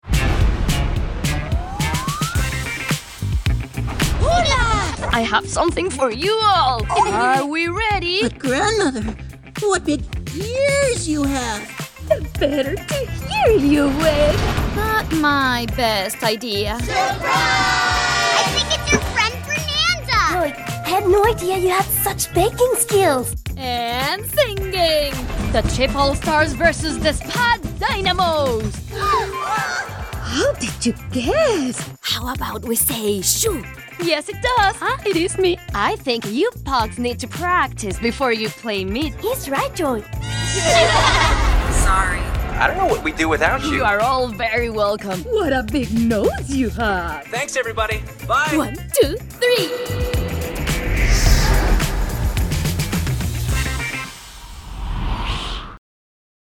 A selection of voiceover demos in English is available here.
Animation - Demo Reel